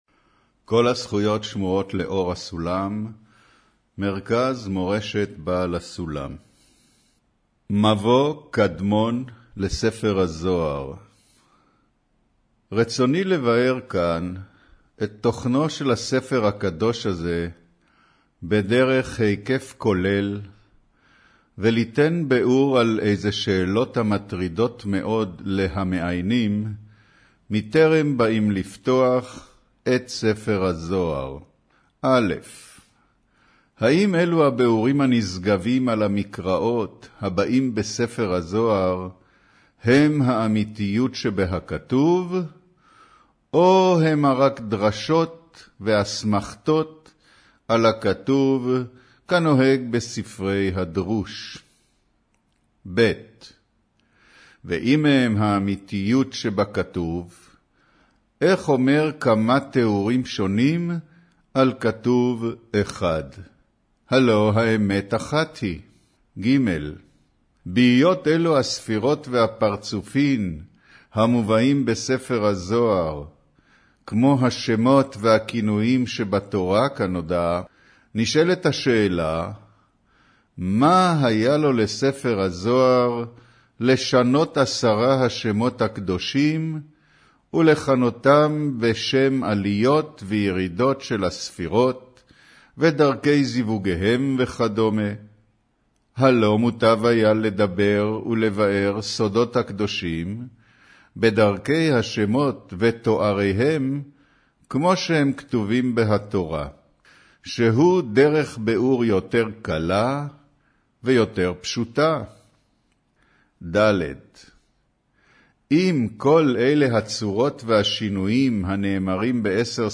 אודיו - קריינות מבוא קדמון לספר הזהר